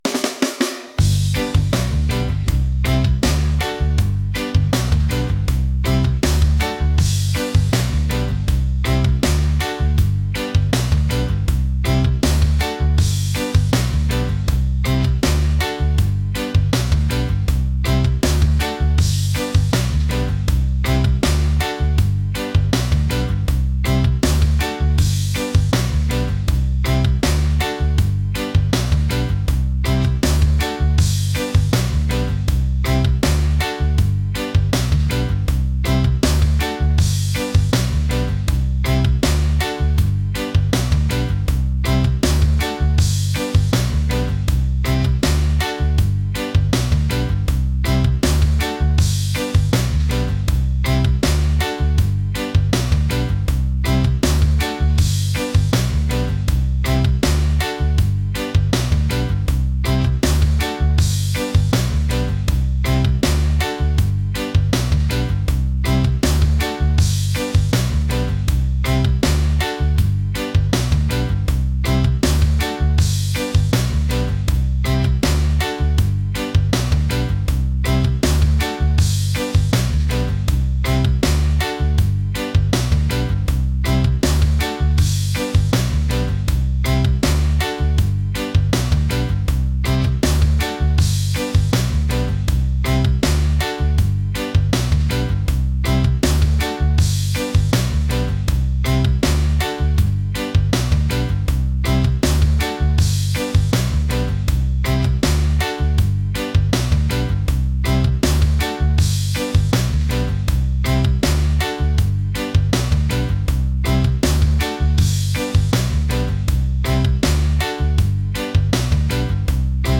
catchy | reggae | upbeat